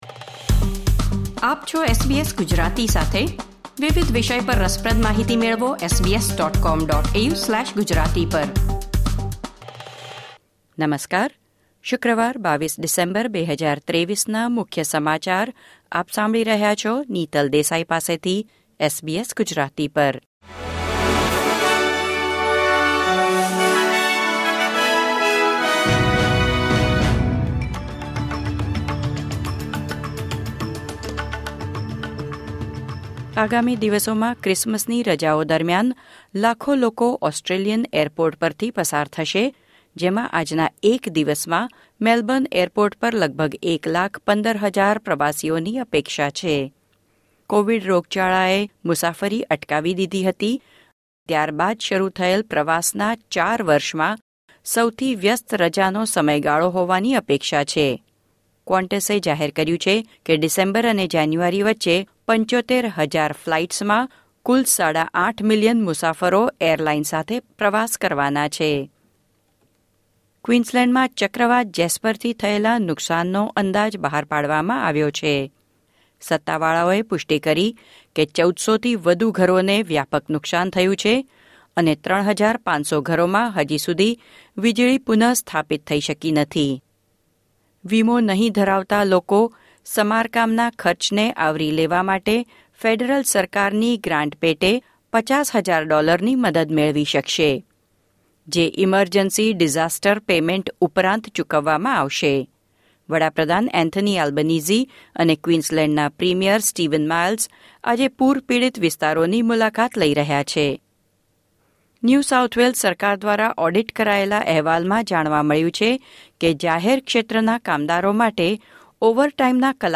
SBS Gujarati News Bulletin 22 December 2023